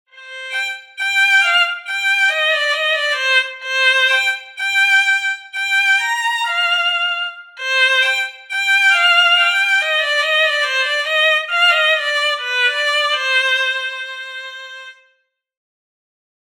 Surdynka
To mały, wąski instrument smyczkowy, podobny do skrzypiec.
Surdynka nie transponuje; w stroju skrzypcowym lub wyższym
Dźwięk powstaje poprzez pocieranie strun smyczkiem.
Dźwięki instrumentów są brzmieniem orientacyjnym, wygenerowanym w programach:
Surdynka-pochette.mp3